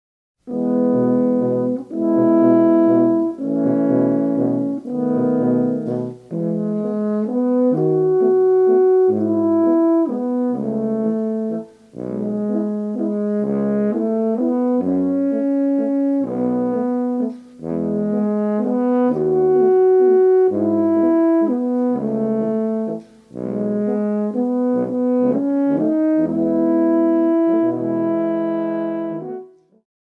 Horn, Keyboards
Pedal Steel
Percussion